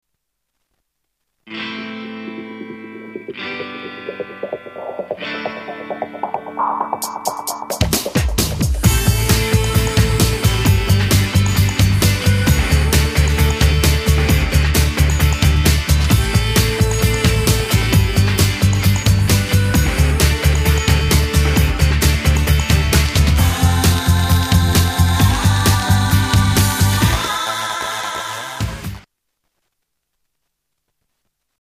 STYLE: Pop
Poppy Eurodance/electronica indie worship.